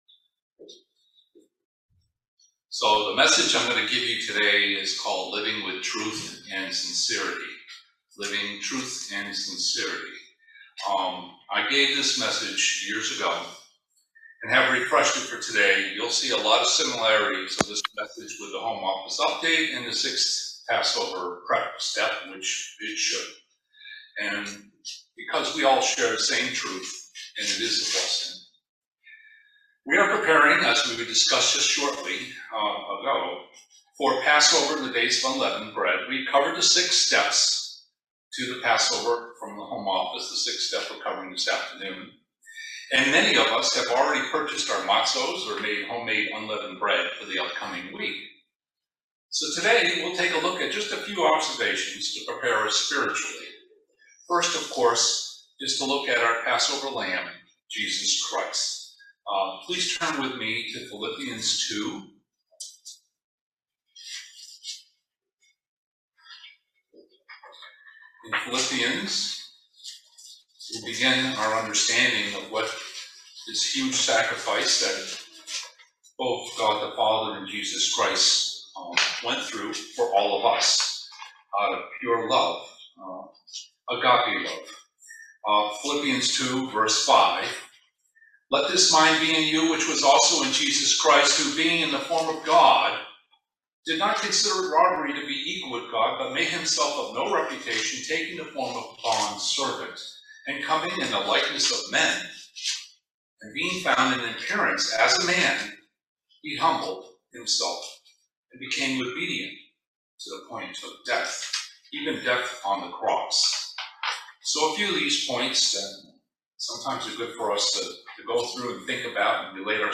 Webcast Sermons